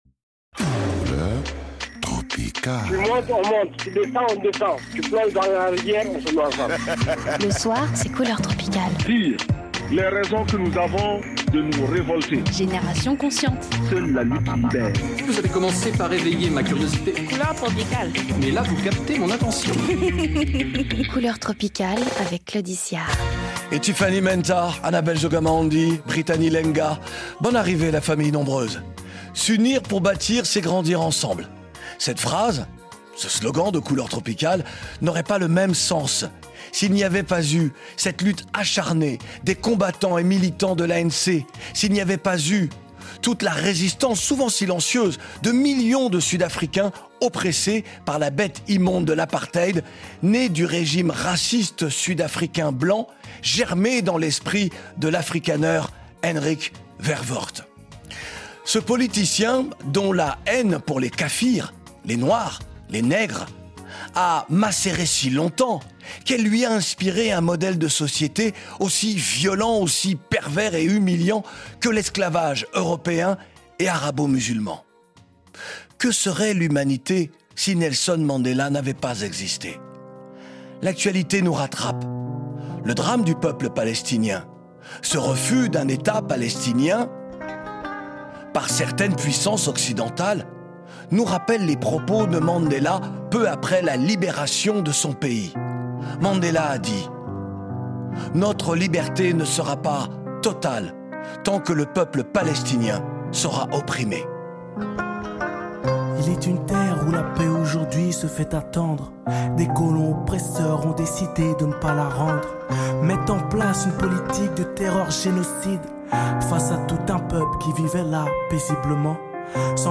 Emission spéciale consacrée à la vie, au combat de Nelson Mandela. Retour en chansons sur le destin de l’un des êtres les plus exceptionnels de l’Histoire de l’humanité.